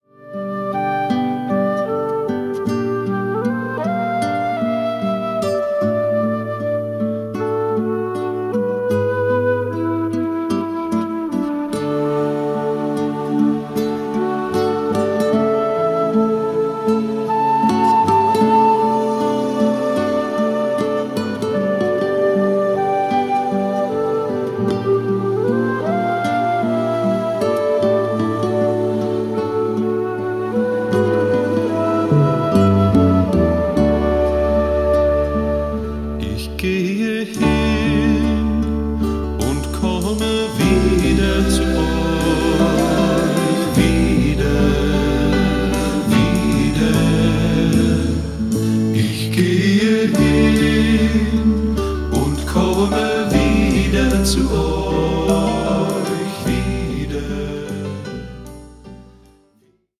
Original-Schallplattenaufnahme aus dem Jahr 1974.
Pop